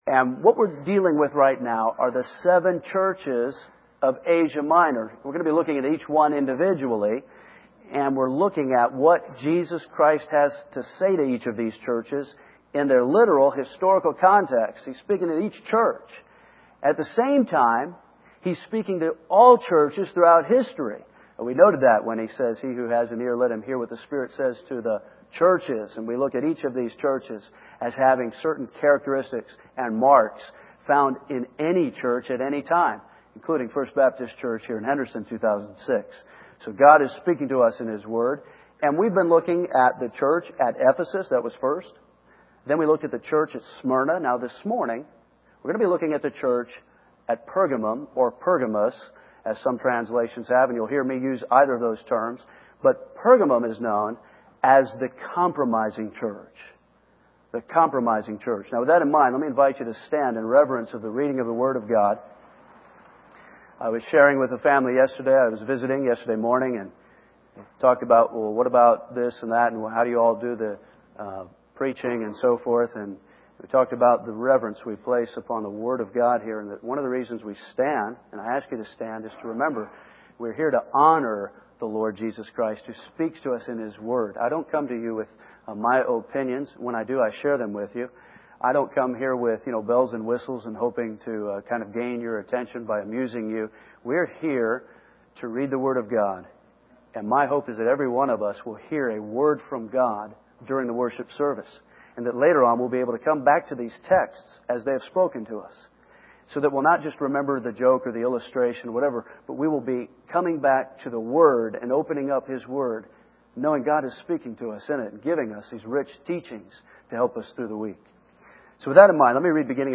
First Baptist Church, Henderson KY